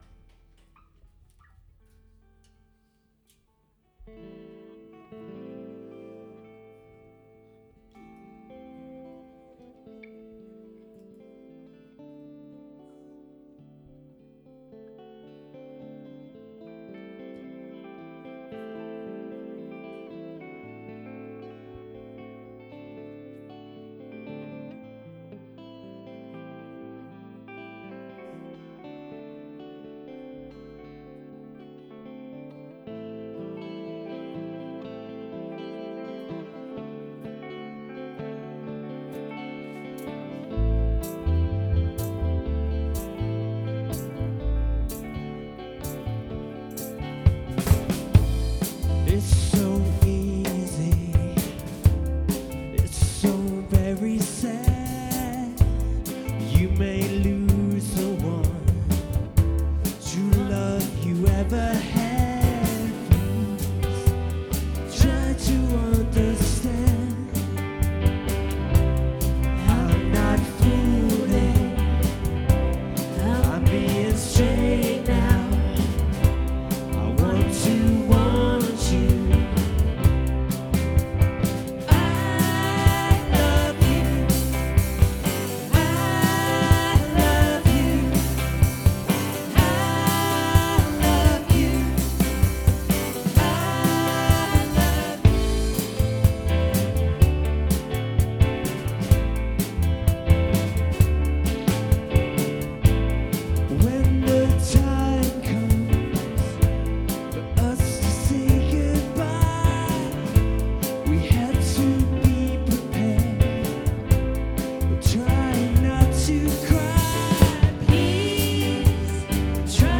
POMME D'OR CONCERT